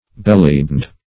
Bellybound \Bel"ly*bound`\